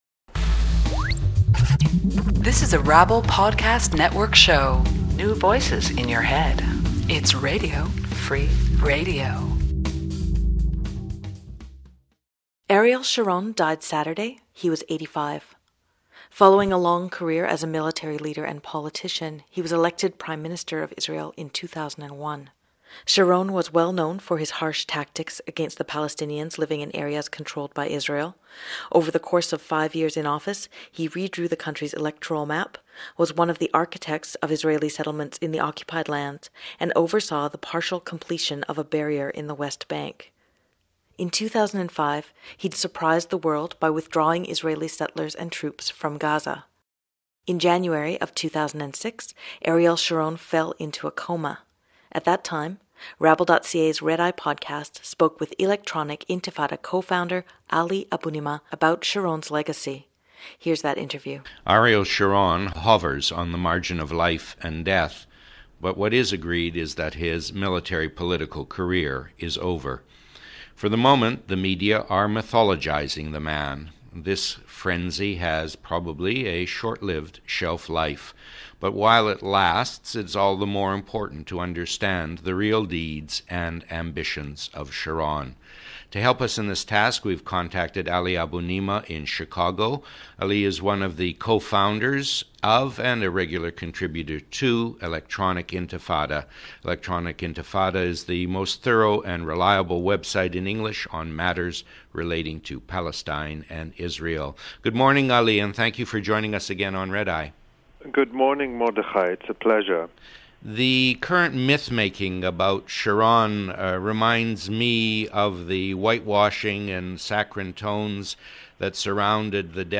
ariel_sharons_legacy_interview_from_rabble_archives.mp3